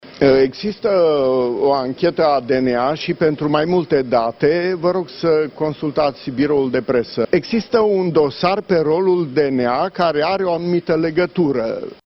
Procurorul general, Augustin Lazăr, a afirmat azi, la sediul CSM, că la DNA există în investigare un dosar în legătură cu adoptarea ordonanței de urgență: